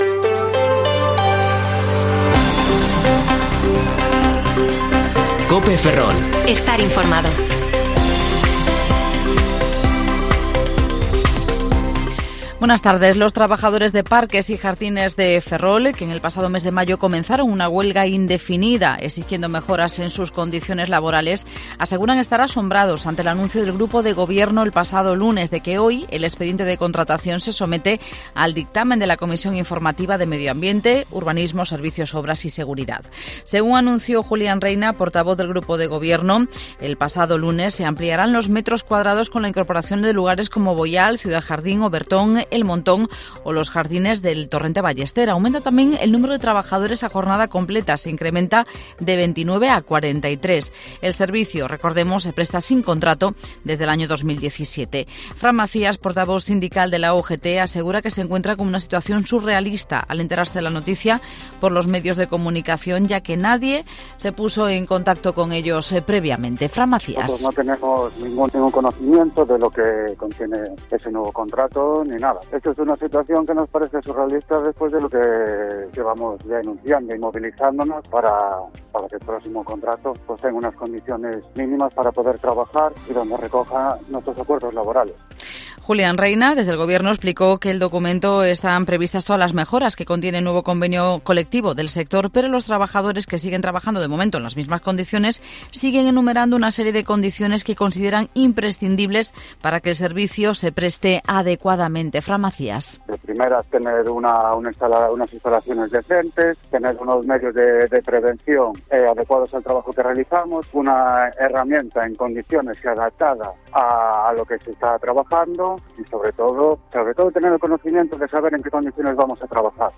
Informativo Mediodía COPE Ferrol 20/7/2022 (De 14,20 a 14,30 horas)